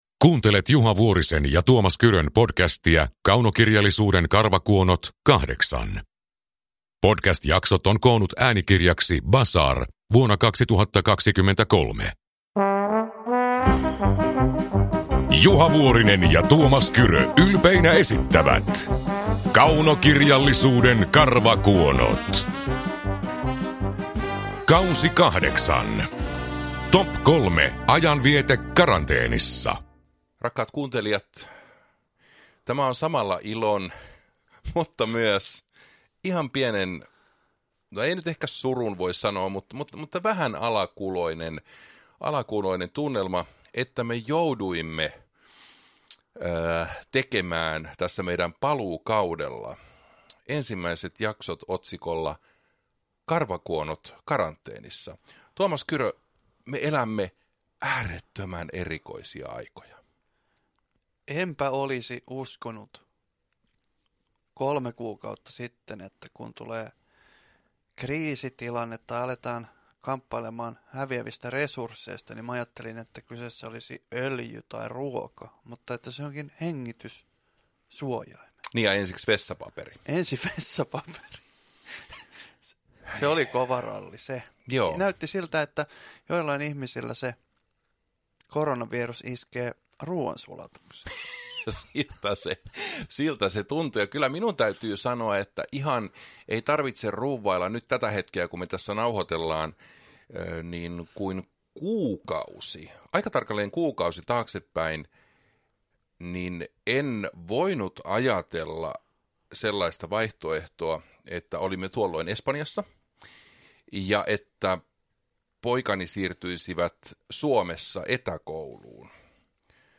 Kaunokirjallisuuden karvakuonot K8 – Ljudbok
Uppläsare: Tuomas Kyrö, Juha Vuorinen